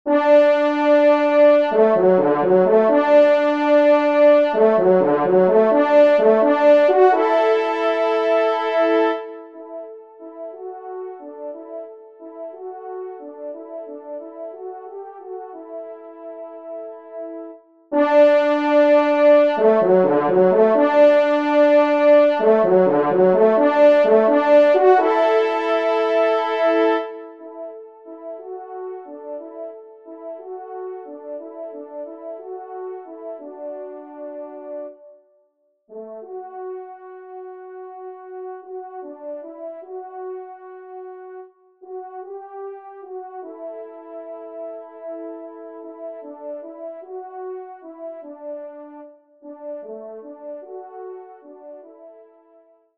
1e et 2eTrompe